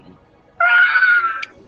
Play Gulliver - (Assustado) Ah! - SoundBoardGuy